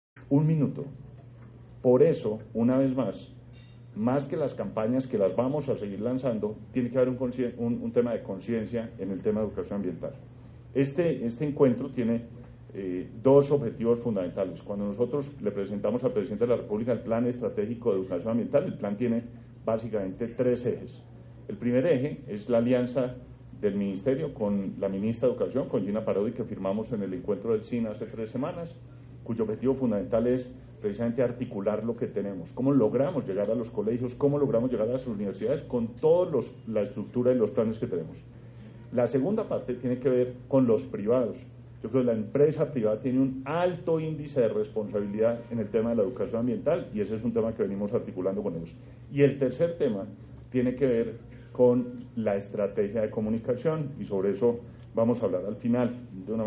Declaraciones del Ministro de Ambiente y Desarrollo Sostenible, Gabriel Vallejo López
12-voz_ministro_mp3cut.net2_.mp3